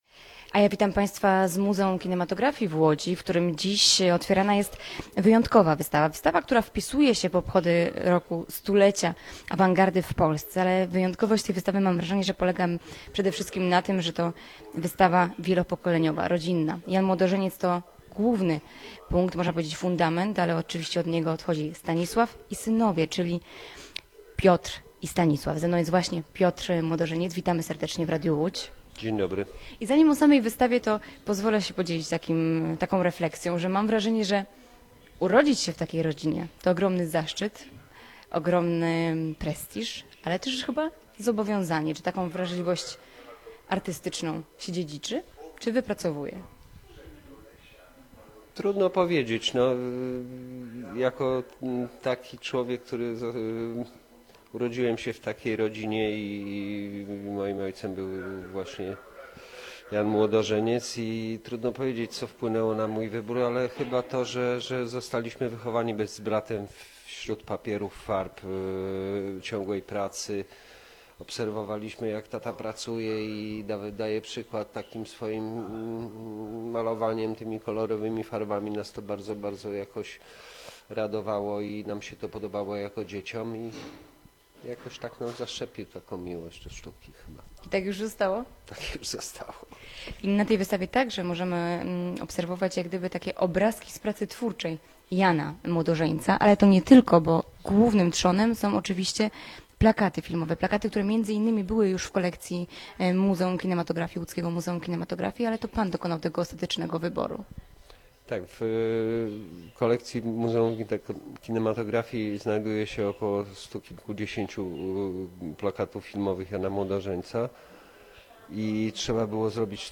Młodożeńcy. Trzypokoleniowa wystawa w Muzeum Kinematografii [ROZMOWA] - Radio Łódź
w Muzeum Kinematografii w Łodzi